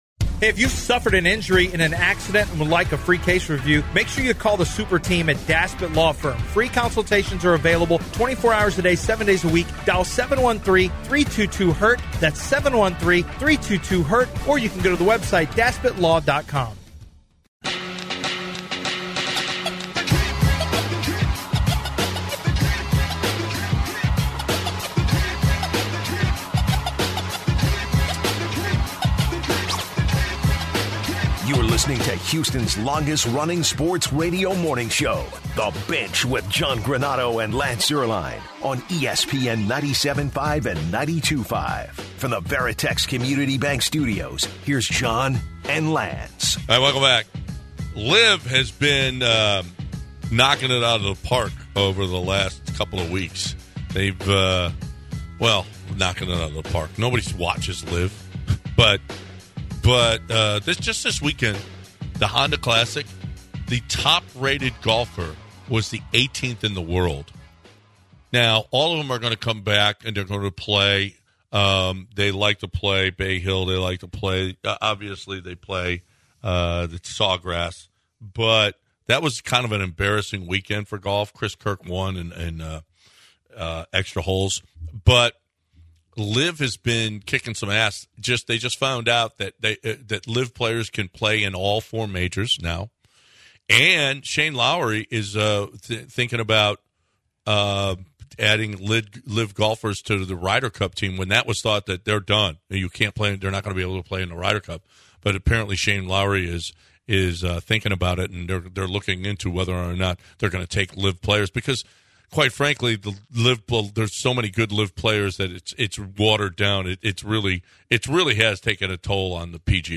listeners sharing their opinion. The Houston Rockets signing Willie Cauley which is a Veteran Big man. Finishes off the show with the amazing news of the weird.